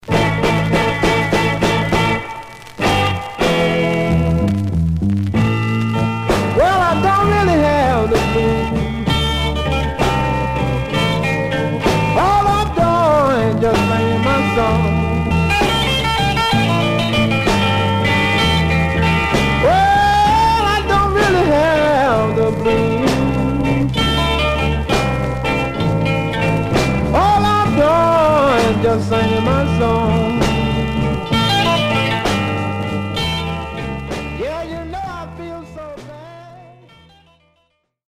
Stereo/mono Mono
Rythm and Blues Condition